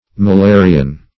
Malarial \Ma*la"ri*al\, Malarian \Ma*la"ri*an\, Malarious